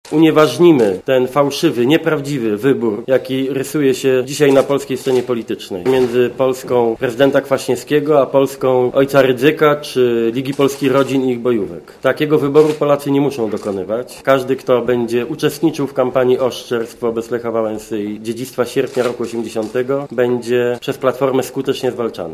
Mówi Donald Tusk z PO
Co do tego nikt w Polsce nie może mieć wątpliwości - oświadczył lider PO na konferencji prasowej w gdańskim biurze byłego prezydenta.